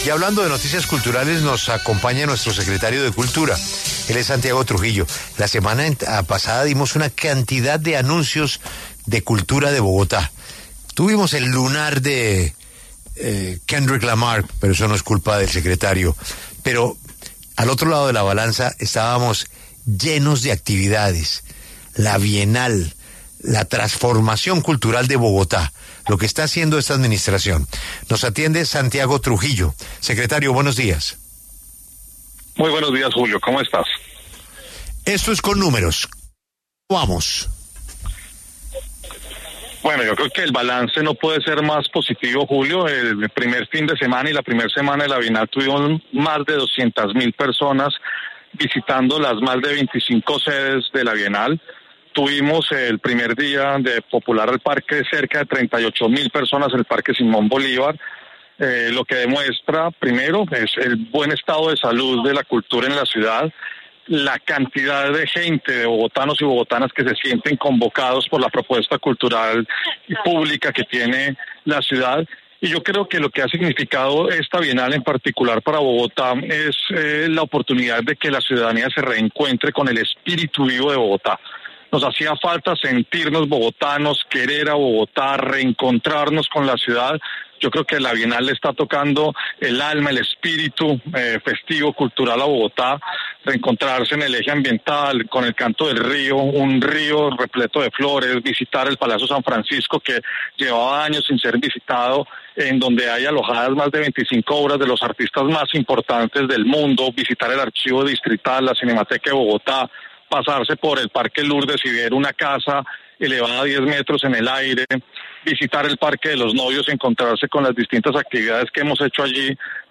Ante este evento, el secretario de Cultura de Bogotá, Santiago Trujillo habló en los micrófonos de W Radio sobre la acogida de los ciudadanos a este evento.